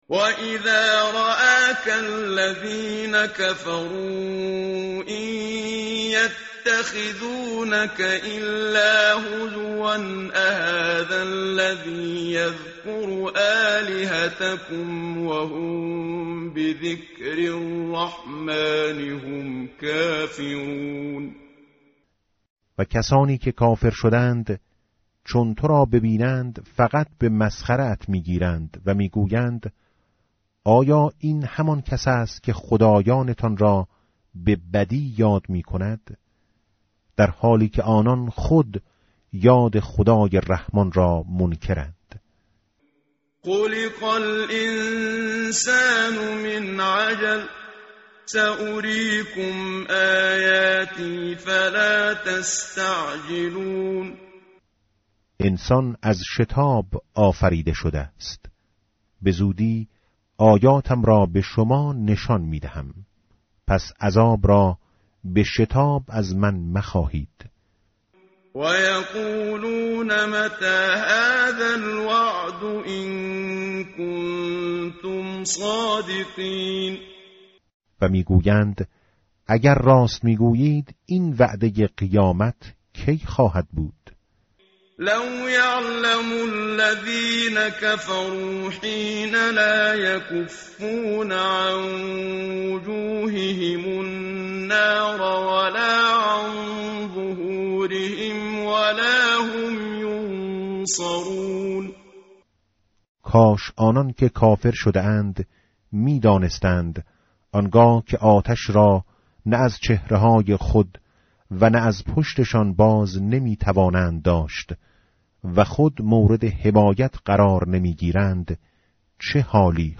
متن قرآن همراه باتلاوت قرآن و ترجمه
tartil_menshavi va tarjome_Page_325.mp3